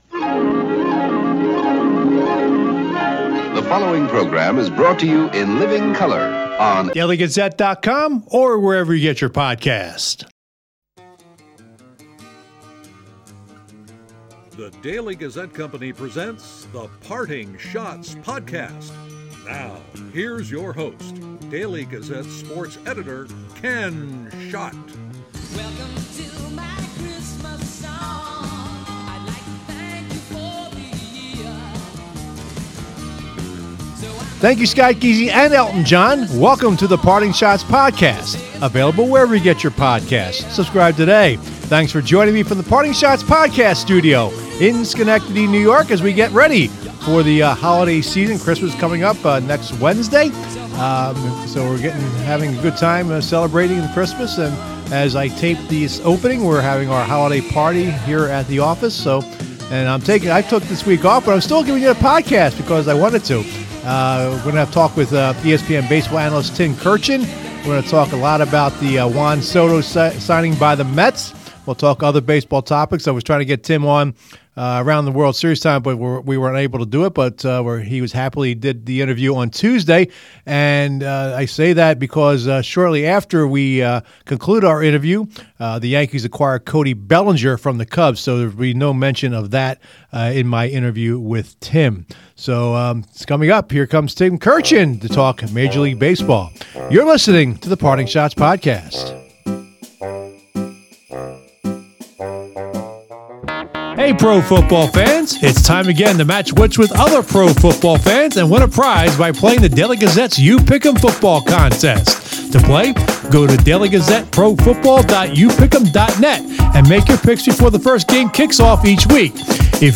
ESPN's Tim Kurkjian discusses Mets signing Soto, other MLB news